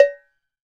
Index of /90_sSampleCDs/NorthStar - Global Instruments VOL-2/CMB_CwBell+Agogo/CMB_CwBell+Agogo